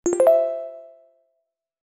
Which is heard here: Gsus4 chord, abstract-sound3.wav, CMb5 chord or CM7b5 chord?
abstract-sound3.wav